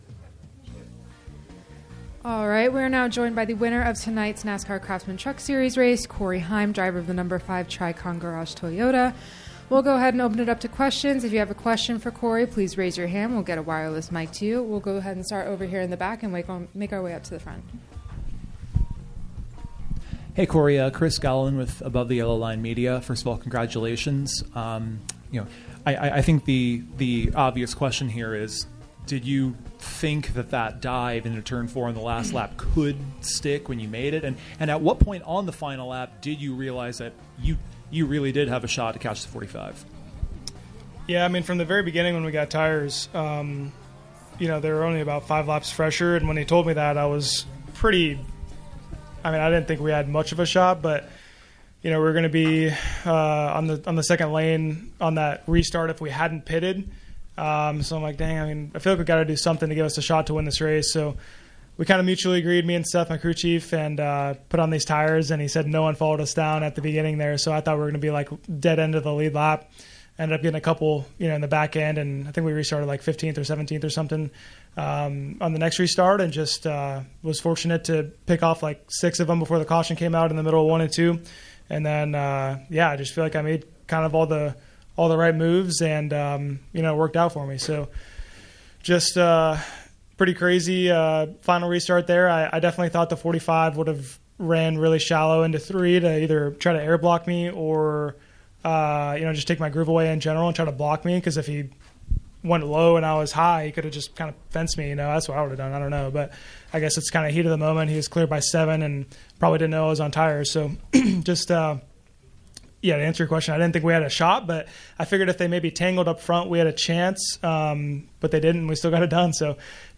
Interview: Corey Heim (No. 5 TRICON Garage Toyota)